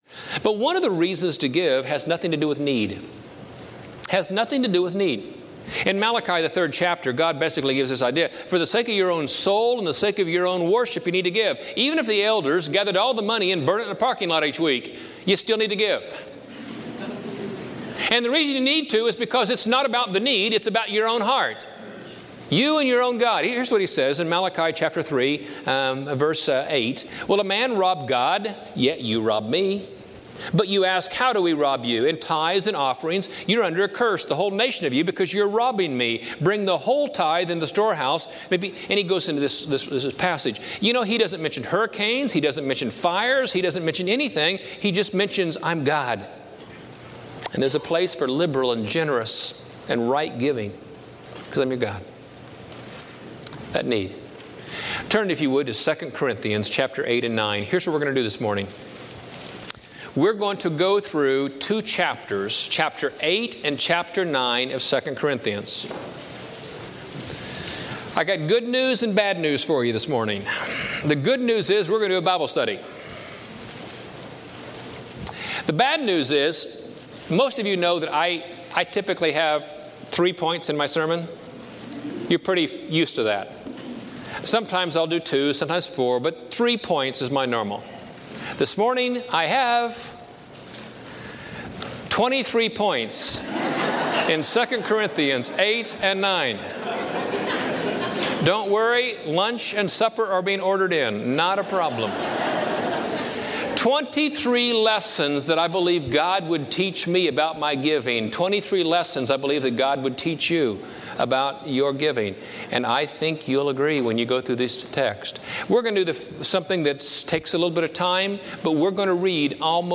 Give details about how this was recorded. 23 Lessons on Giving from 2 Corinthians 8-9 Preached at College Heights Christian Church September 18, 2005 Series: Scripture: 2 Corinthians 8-9 Audio